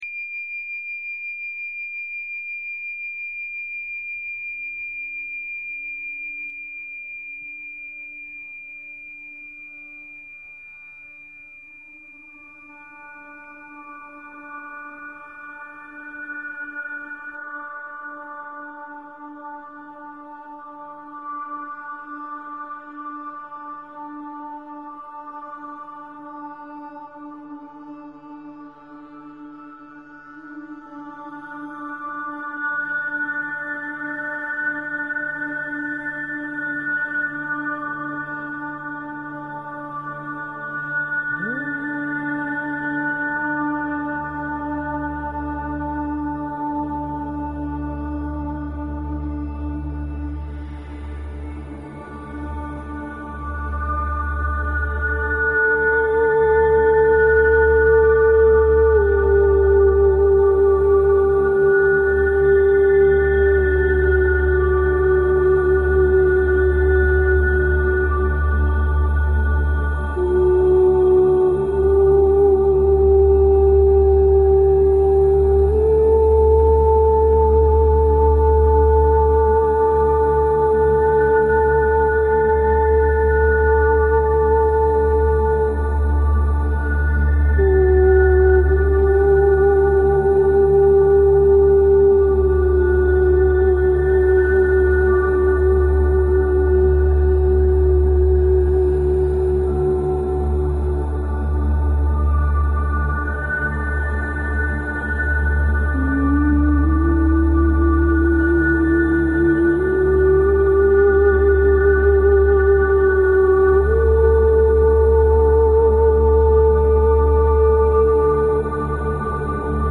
Talk Show Episode, Audio Podcast, Radiance_by_Design and Courtesy of BBS Radio on , show guests , about , categorized as
Radiance By Design is specifically tailored to the energies of each week and your calls dictate our on air discussions. Together we explore multi-dimensional realities, healing through energy structures, chakra tuning, the complexities